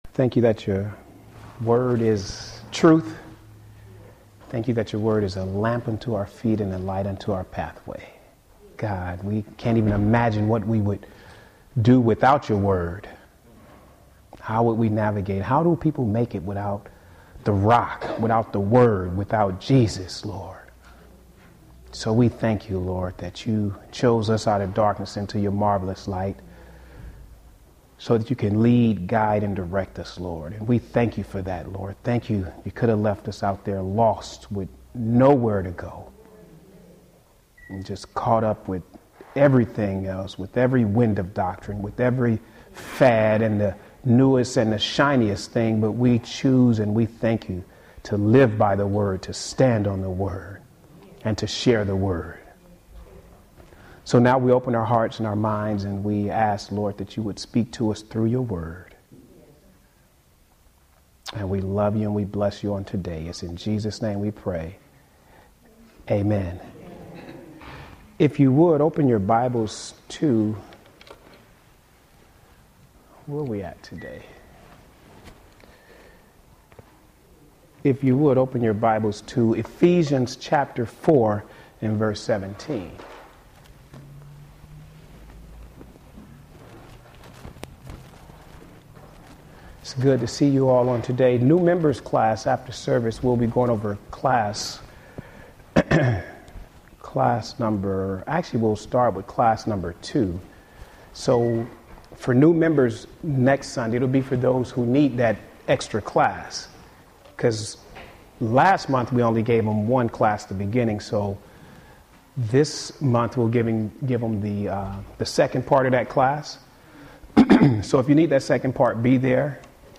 Home › Sermons › The New Vs. The Old Part 1